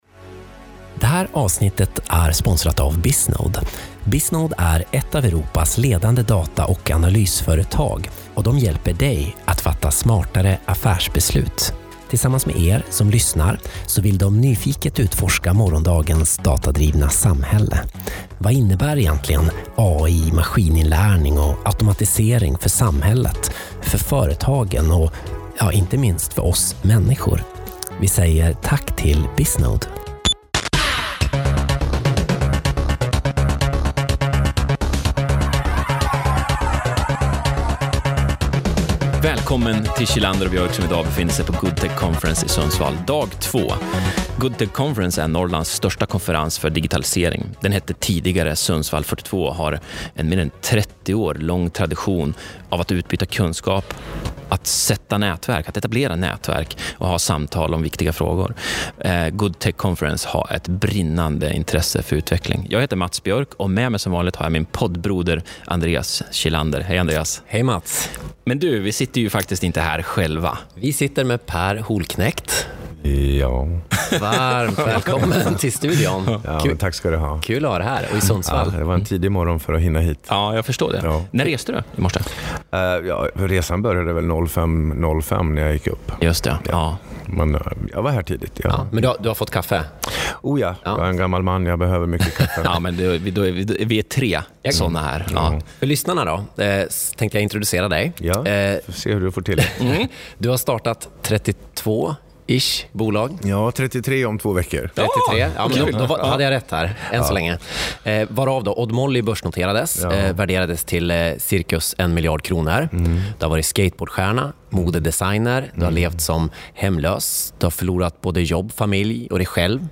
Vi pratar i nära 30 minuter om konsten att vara sig själv och vilka konsekvenser det får – i alla sammanhang och situationer. En s.k. ”must listen” om du väljer att lyssna på bara ett av våra samtal från Good Tech Conference.